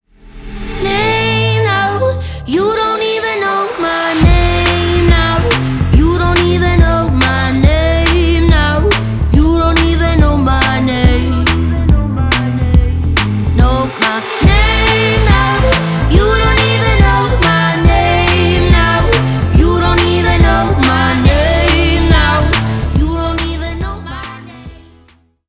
бесплатный рингтон в виде самого яркого фрагмента из песни